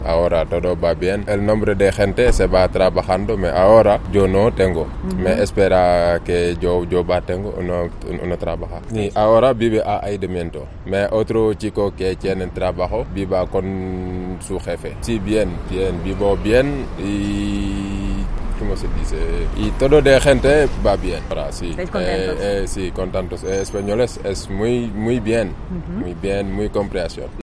La vendimia se acelera en La Rioja. A punto de generalizarse con más de 90 millones de kilos de uva ya recogidos, esuchamos la voz de los temporeros que trabajan en nuestros campo